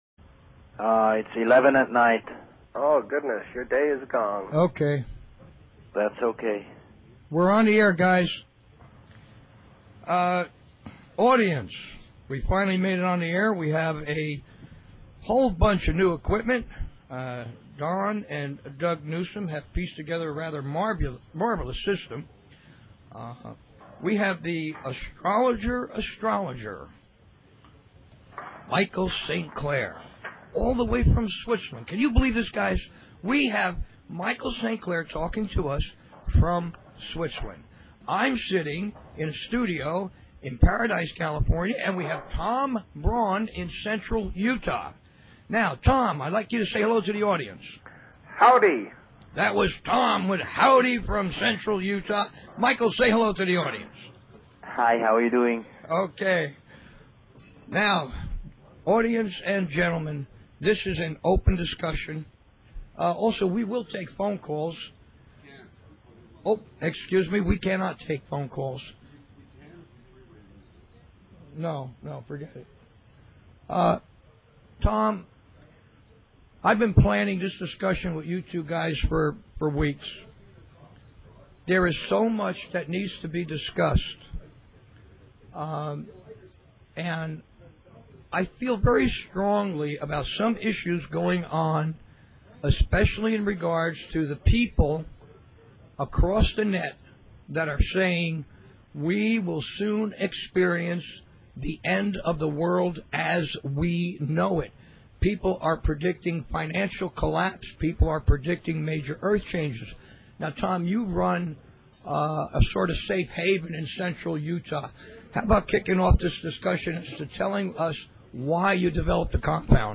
Talk Show Episode, Audio Podcast, BBS_Expeditions and Courtesy of BBS Radio on , show guests , about , categorized as